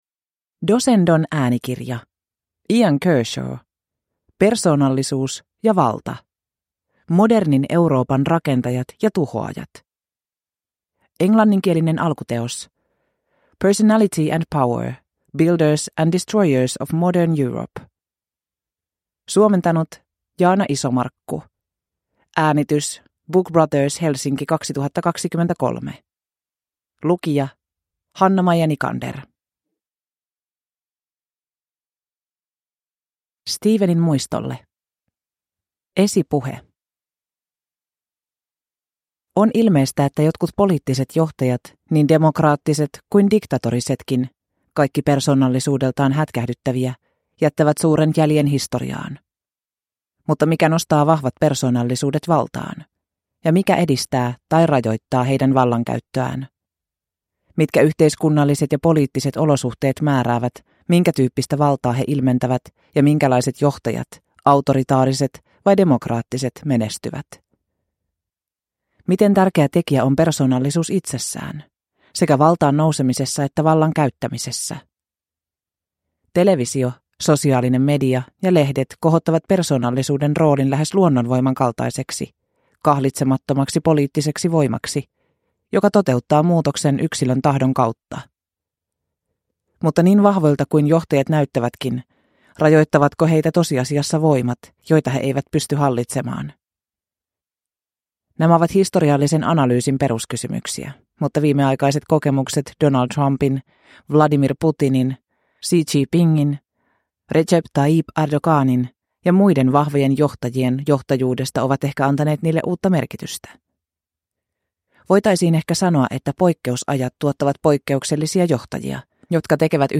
Persoonallisuus ja valta – Ljudbok – Laddas ner